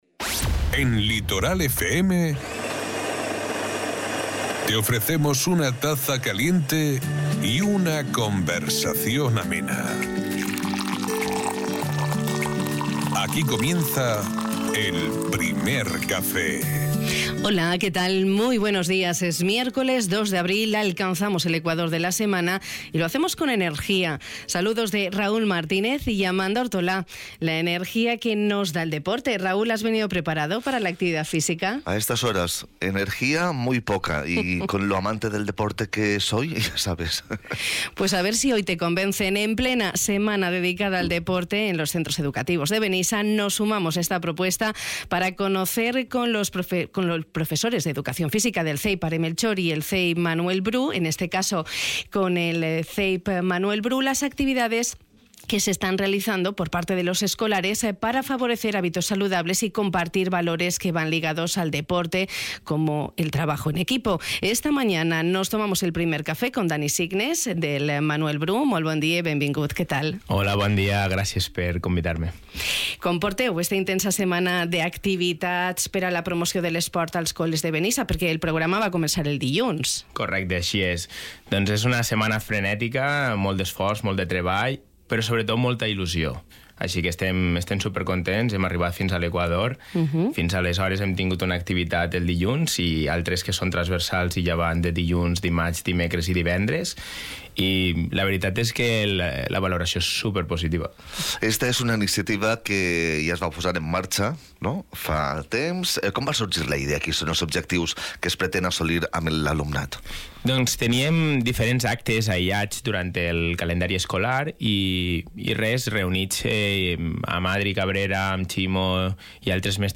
En plena semana dedicada al deporte en los centros educativos de Benissa, desde el Primer Café de Radio Litoral nos hemos sumado a esta propuesta para conocer las actividades que están realizando los escolares para favorecer hábitos saludables y compartir valores que van ligados al deporte, como el trabajo en equipo.